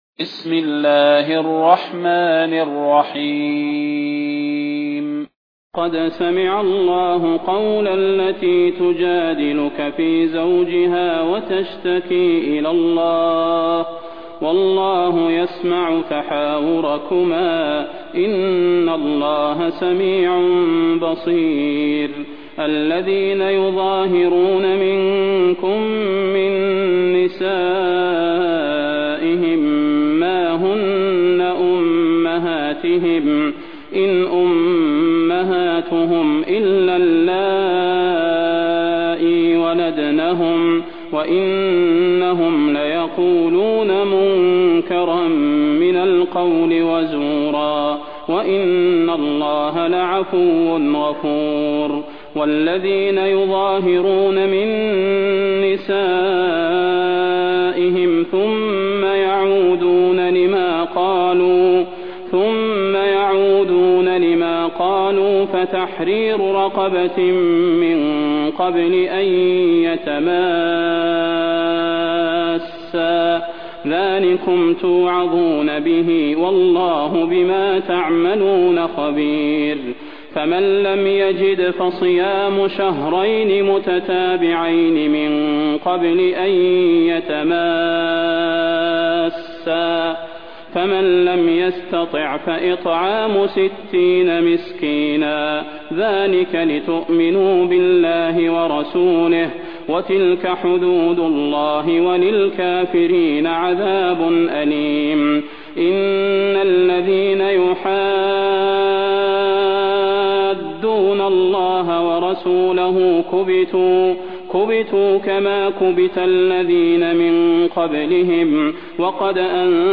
المكان: المسجد النبوي الشيخ: فضيلة الشيخ د. صلاح بن محمد البدير فضيلة الشيخ د. صلاح بن محمد البدير المجادلة